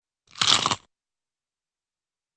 Download Crunchy Bite sound effect for free.
Crunchy Bite